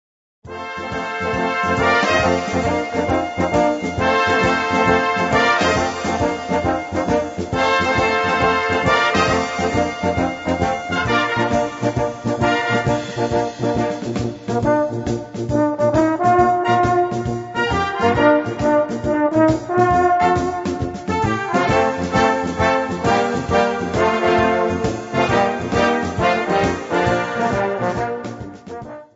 Gattung: Volkstümlicher Schlager
Besetzung: Blasorchester